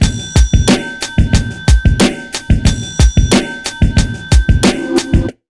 Index of /90_sSampleCDs/USB Soundscan vol.02 - Underground Hip Hop [AKAI] 1CD/Partition A/11-91BEATMIX